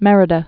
(mĕrĭ-də, mĕrē-dä)